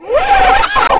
RbtOrbotAlertD.wav